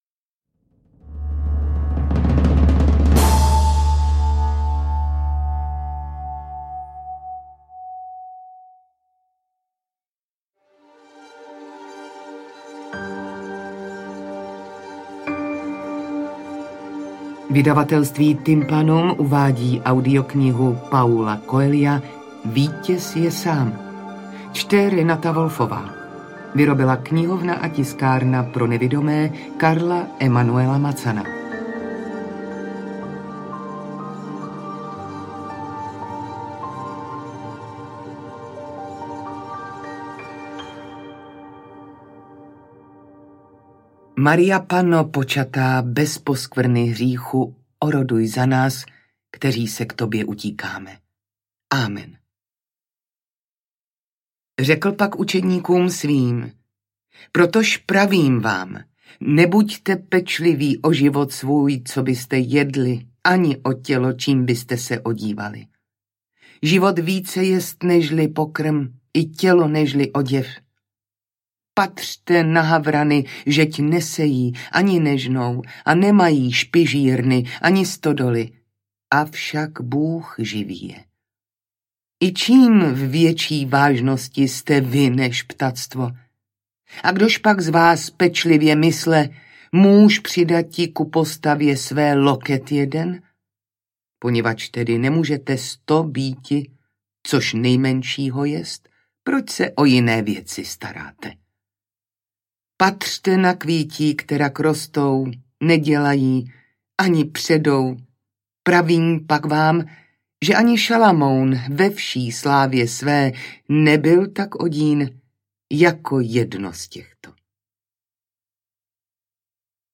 V audioknize Vítěz je sám Paulo Coelho předkládá čtenářům věrný obraz světa, v němž žijeme a kde se natolik upínáme k bohatství a úspěchu za každou cenu, že mnohdy neslyšíme, co nám říká naše vlastní srdce.